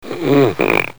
cartoon26.mp3